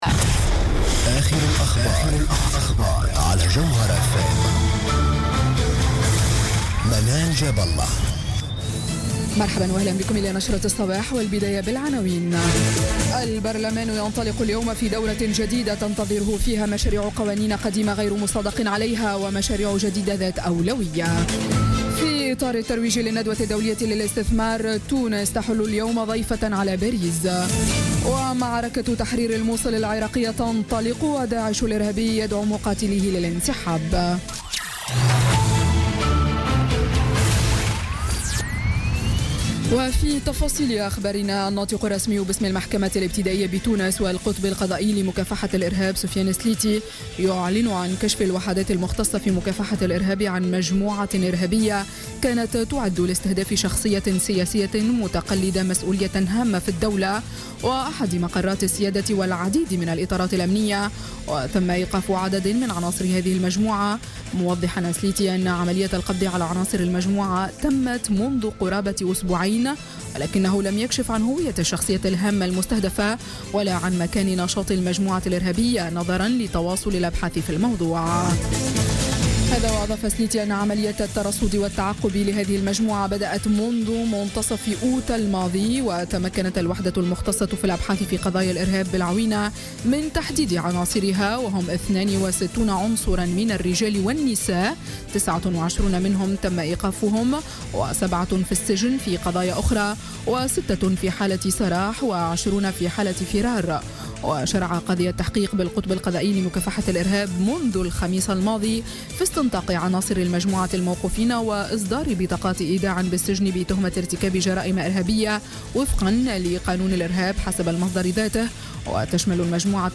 نشرة أخبار السابعة صباحا ليوم الاثنين 17 أكتوبر 2016